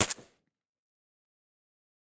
JSRS Sound Mod / gamedata / sounds / weapons / _bolt / pistol_3.ogg
pistol_3.ogg